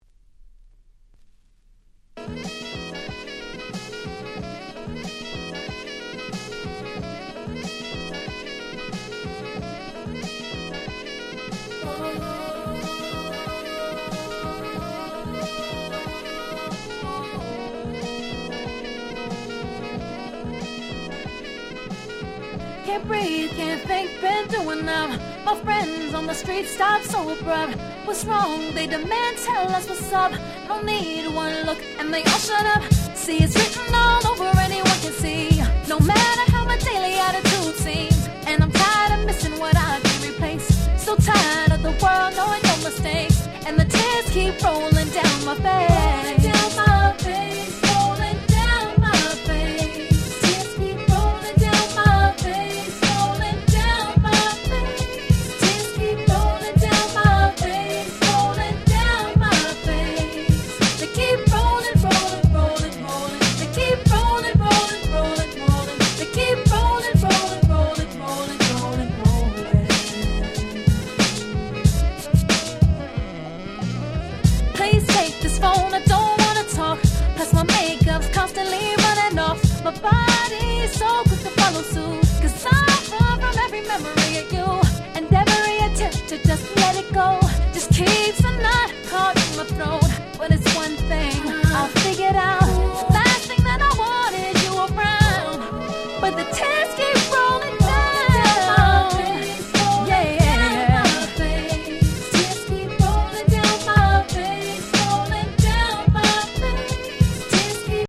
05' Super Hit R&B !!